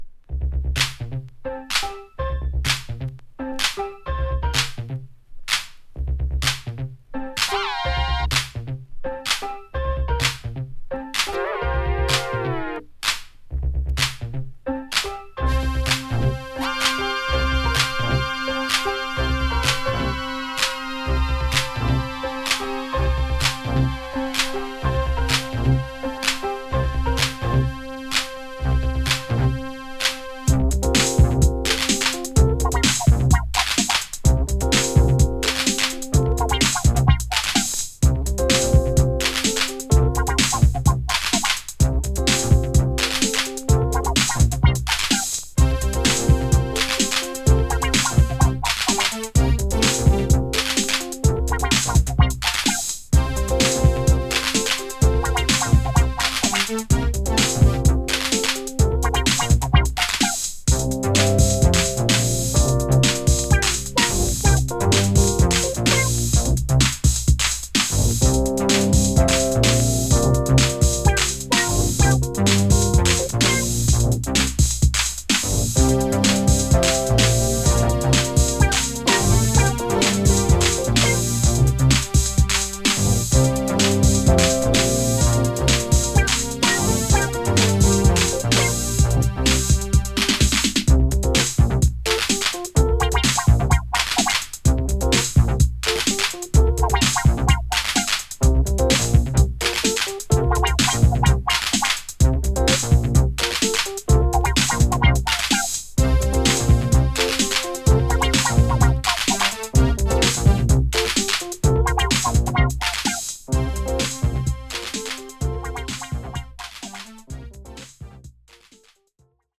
アブストラクトでレフトフィールドなベッドルーム・メロウ・ソウル秘宝プライヴェート・プレス盤85年唯一作!